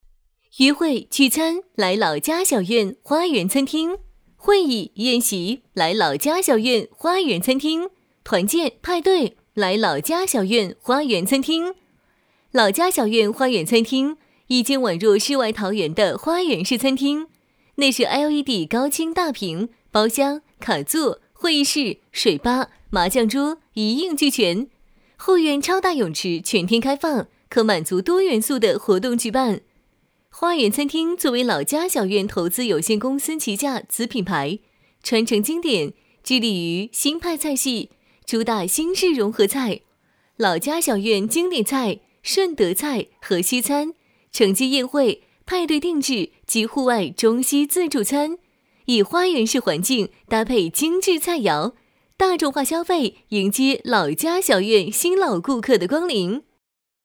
中文女声
• 促销叫卖
• 激情
• 欢快